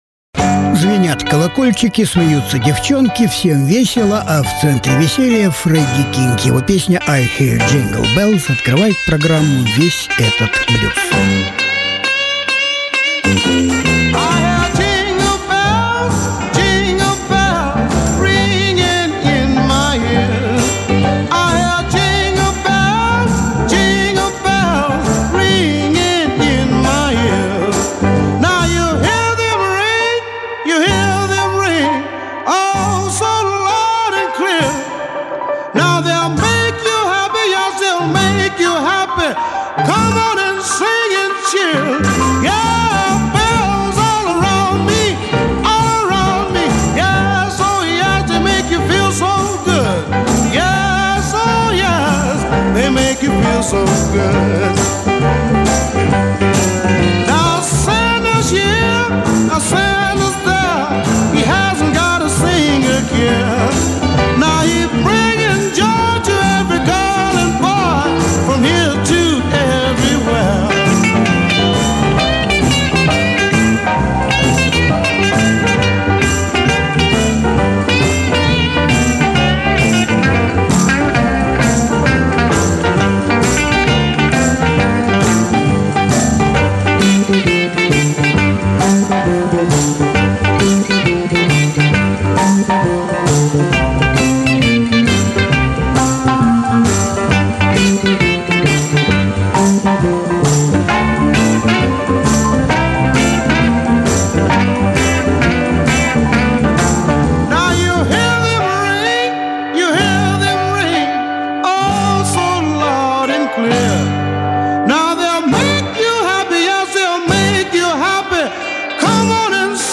Matt-Guitar-Murphy - гитарист, Paul Rodgers - певец.
Разные альбомы Жанр: Блюзы и блюзики СОДЕРЖАНИЕ 30.12.2019 1.